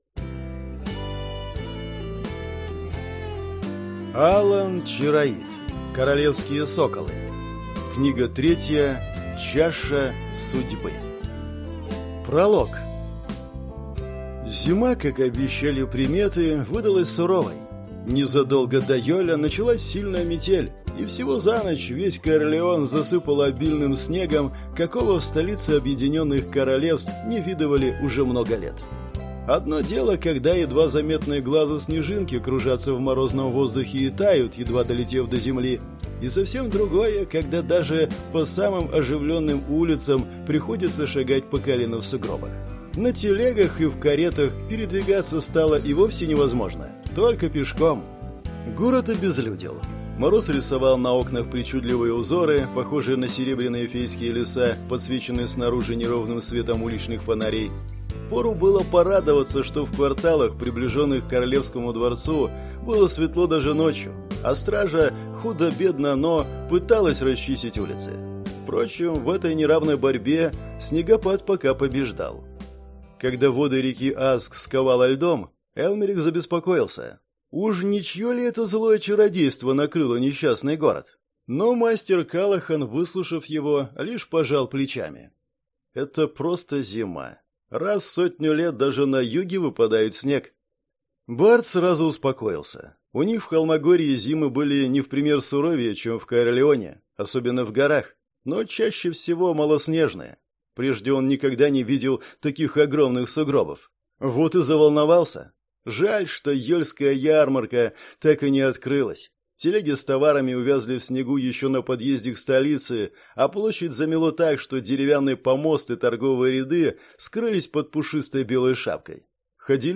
Аудиокнига Чаша судьбы | Библиотека аудиокниг